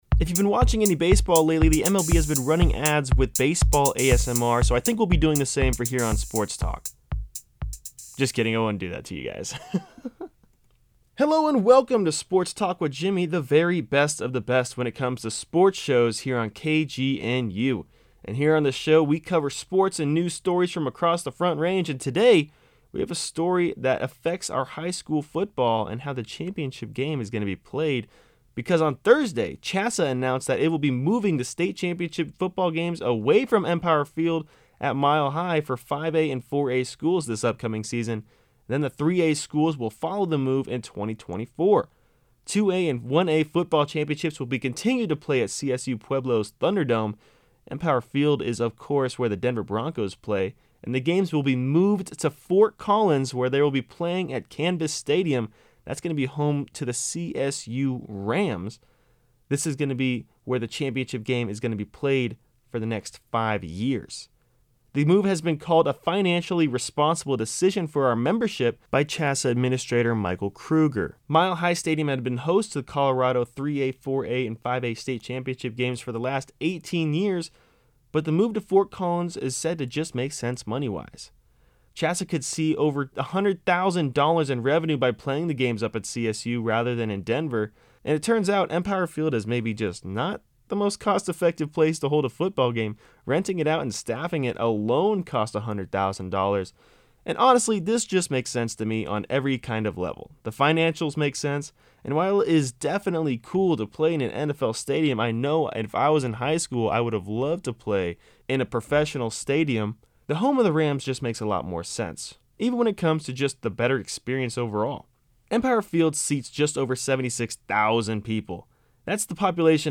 Sports-Talk-4.7-1.mp3